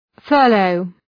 Shkrimi fonetik {‘fɜ:rləʋ}